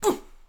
SFX_Battle_Vesna_Defense_12.wav